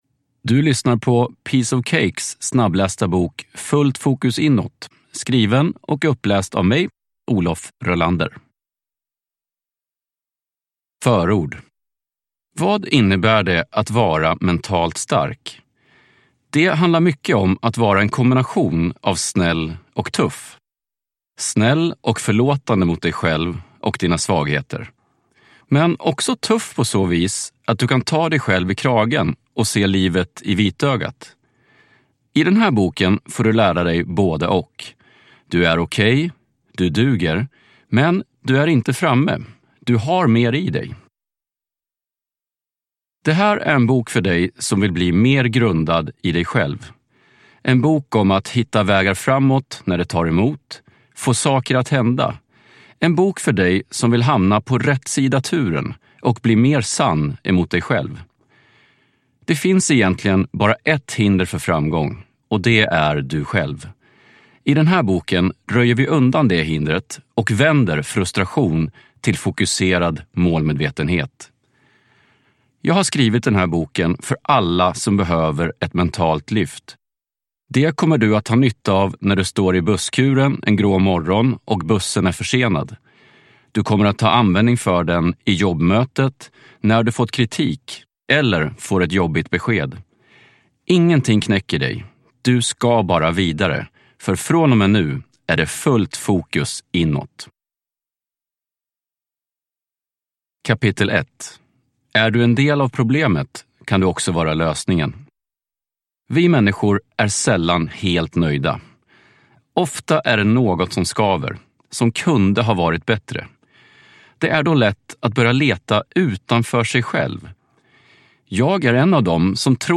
Fullt fokus inåt : så blir du mentalt starkare (ljudbok) av Olof Röhlander